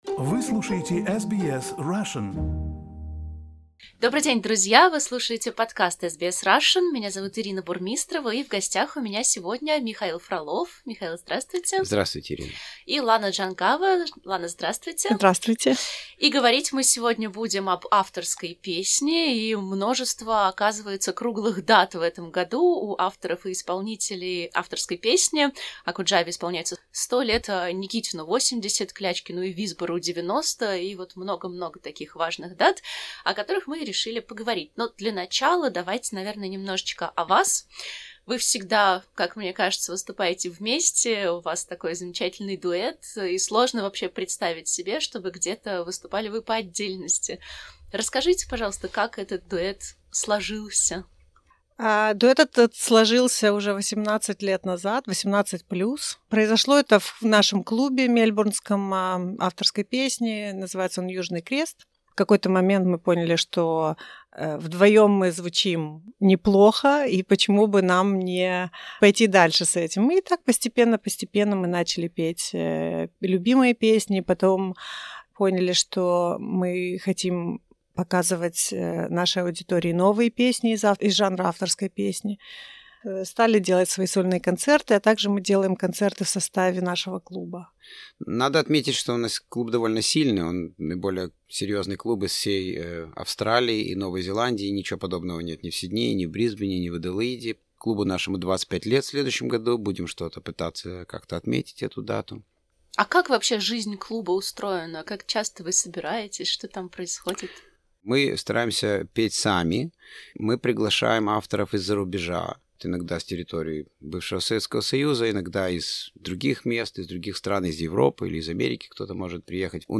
в студии SBS в Мельбурне.